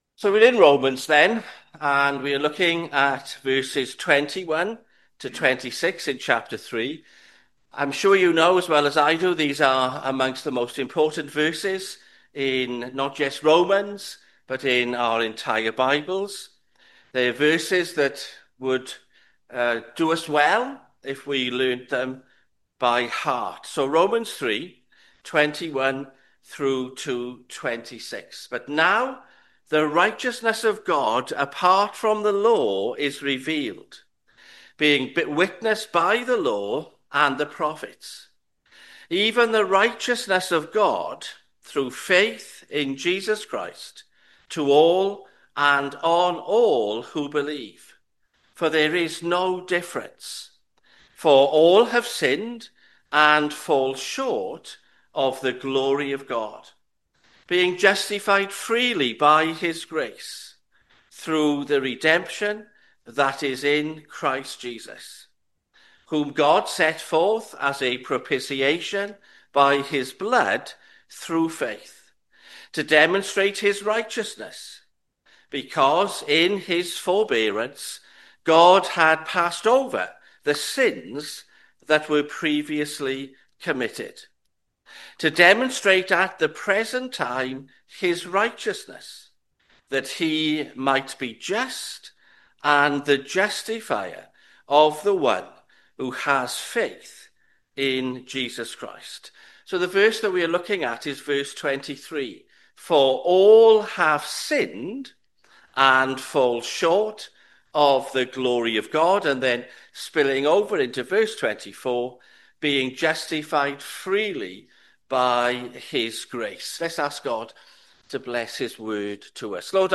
Sermons Tabernacle Church - 13/7/2025 pm - All have sinned Play Episode Pause Episode Mute/Unmute Episode Rewind 10 Seconds 1x Fast Forward 30 seconds 00:00 / 30:08 Subscribe Share RSS Feed Share Link Embed